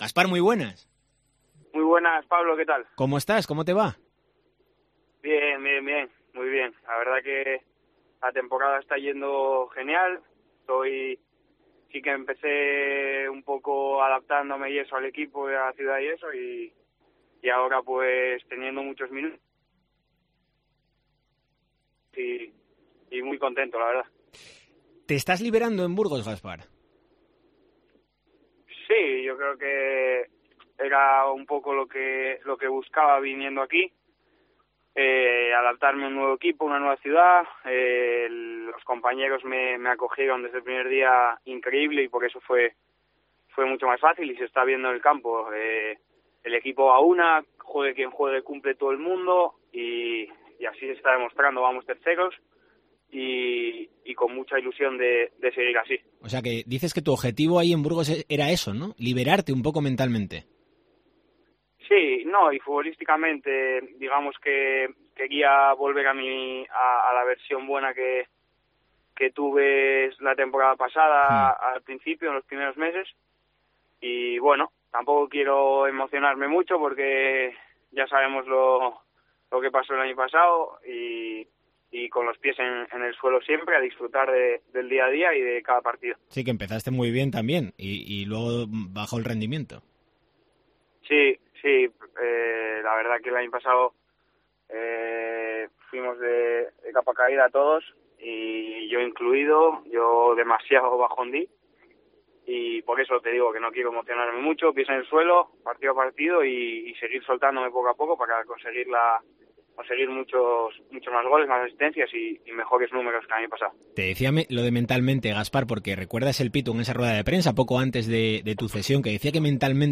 Lo ha expresado este miércoles en una entrevista en Deportes COPE Asturias.